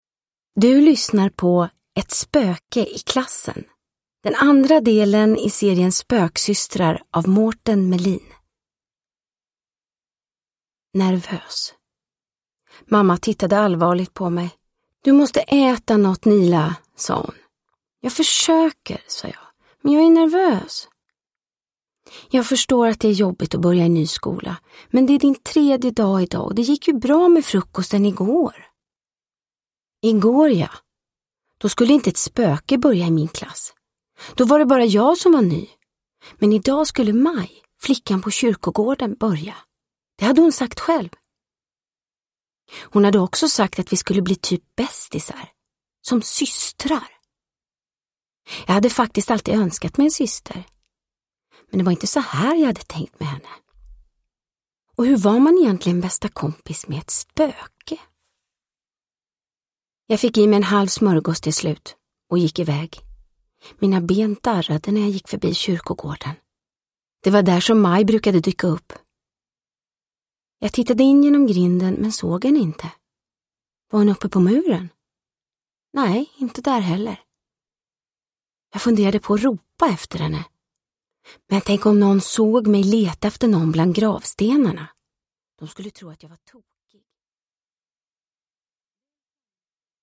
Ett spöke i klassen – Ljudbok – Laddas ner
Uppläsare: Vanna Rosenberg